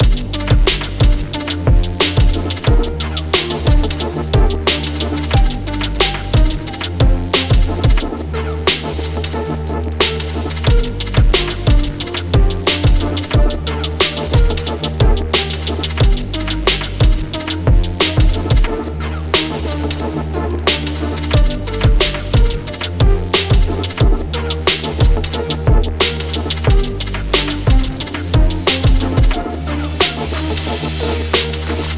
Music - Song Key
G Minor